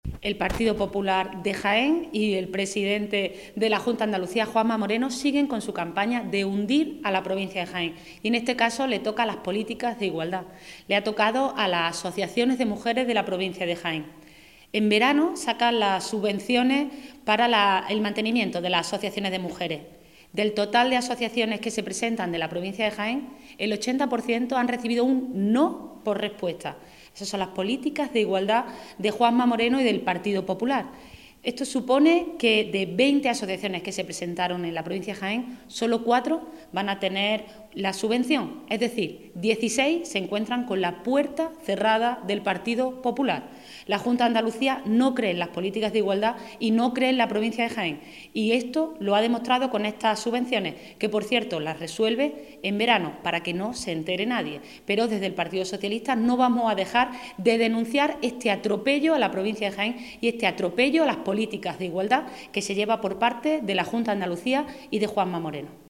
Cortes de sonido
Mercedes Gámez